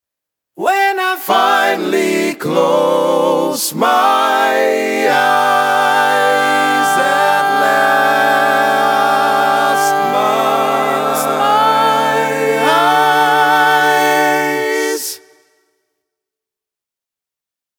Key written in: G Major
How many parts: 4
Type: Barbershop
All Parts mix: